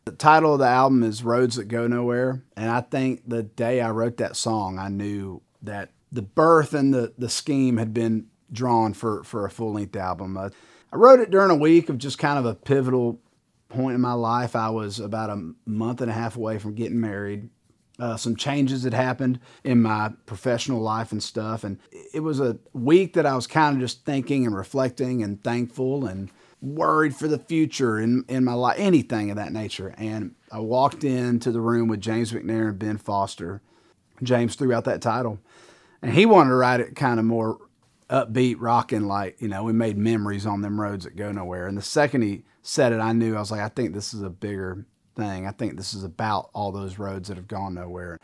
Travis Denning talks about writing the title track of his new album, "Roads That Go Nowhere."